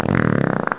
fart.mp3